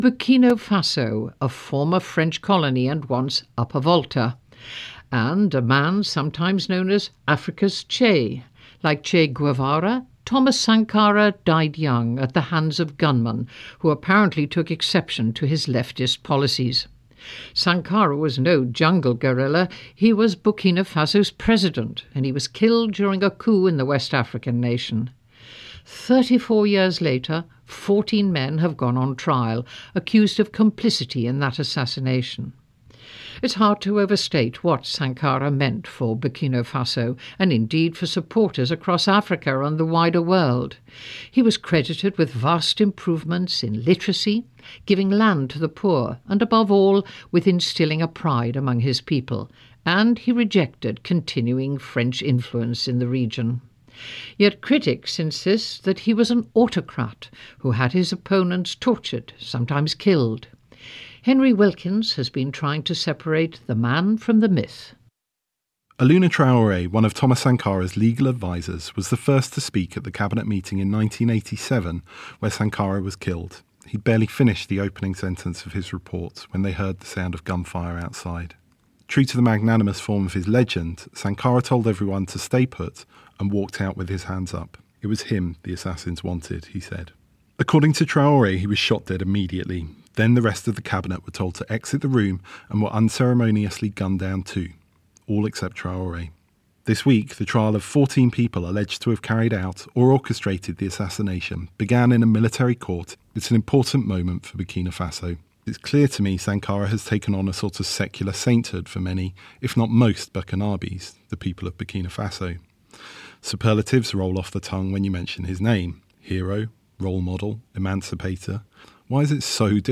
Nice to be introduced by another legend, Kate Adie. 5:08 FOOC CLIP SANKARA